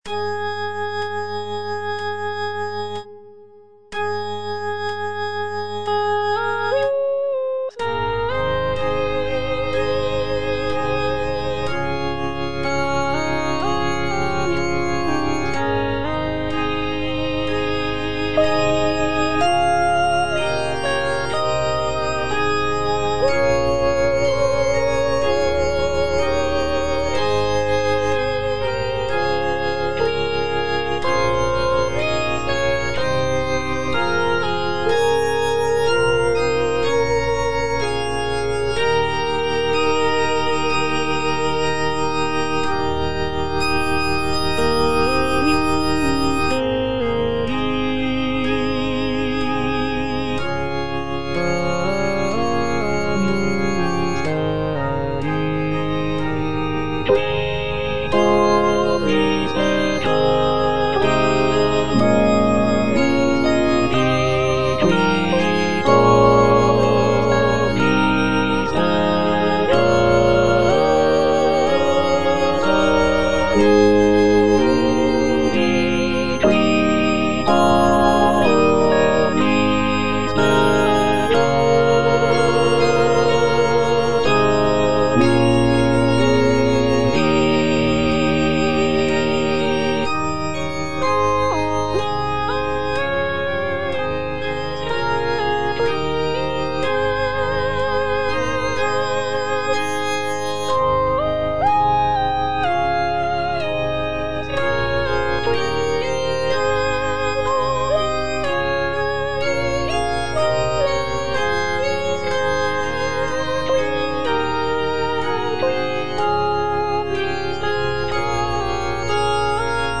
Soprano (Voice with metronome) Ads stop
is a sacred choral work rooted in his Christian faith.